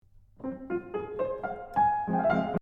Let’s then get back to the beginning of the Op. 2 No. 1 Sonata, which as mentioned starts with a Mannheim Rocket: